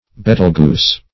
Betelguese \Bet"el*guese\ (b[e^]t"[e^]l*j[=e]z), n. [F.